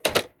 gear_rattle_weap_launcher_08.ogg